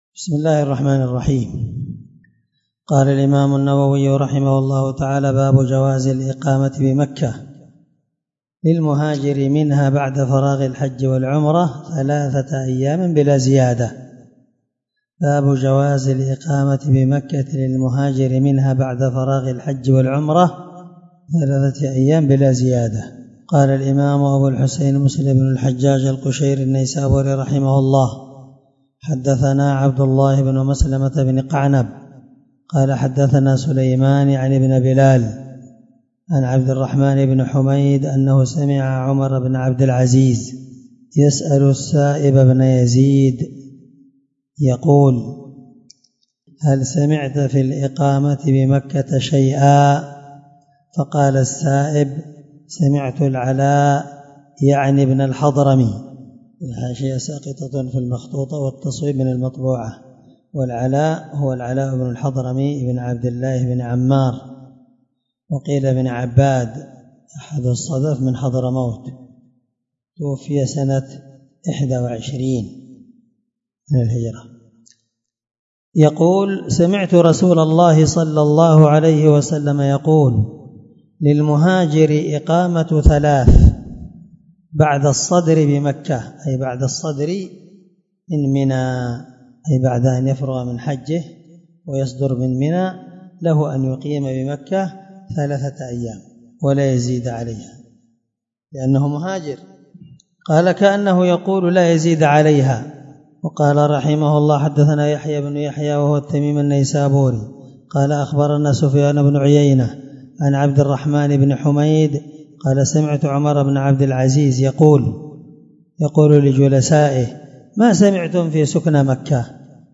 الدرس80من شرح كتاب الحج حديث رقم(1352) من صحيح مسلم